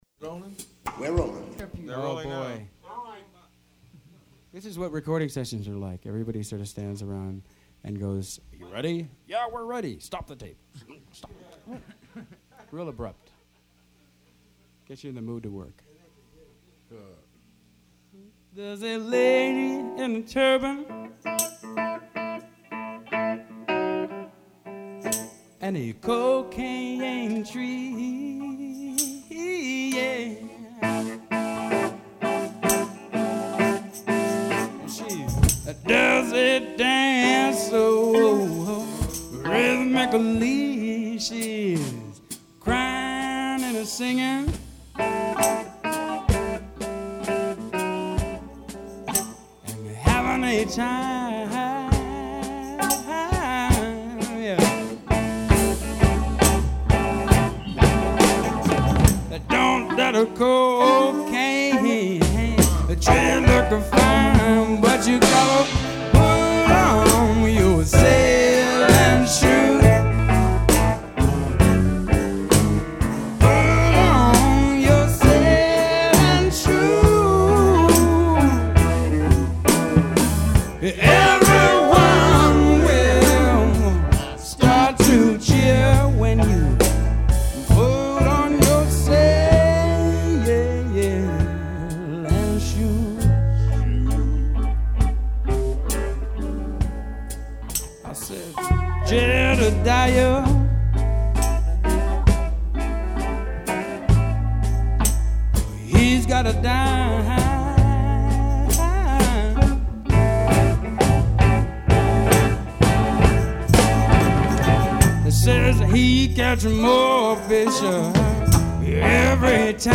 from the master tape